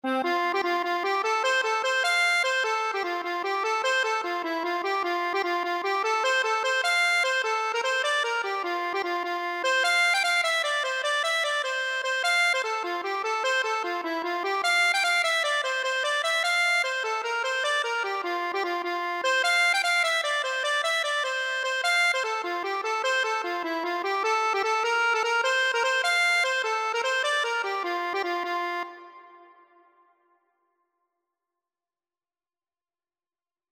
6/8 (View more 6/8 Music)
F major (Sounding Pitch) (View more F major Music for Accordion )
Accordion  (View more Easy Accordion Music)
Traditional (View more Traditional Accordion Music)